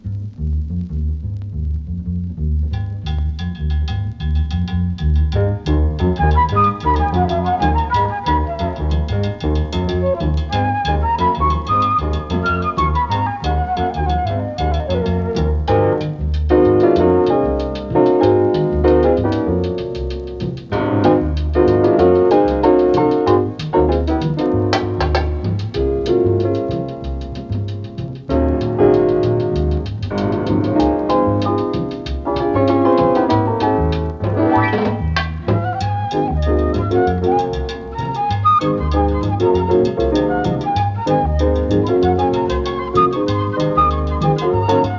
Jazz, Latin, Lounge　USA　12inchレコード　33rpm　Mono